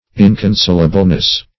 -- In`con*sol"a*ble*ness, n. -- In`con*sol"a*bly, adv.